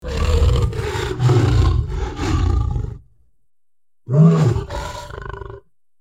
Sound Effect Of Lion Roar Ambience Hd Bouton sonore